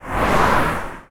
CosmicRageSounds/car2.ogg_c59670a1 at 4f151c074f69b27e5ec5f93e28675c0d1e9f0a66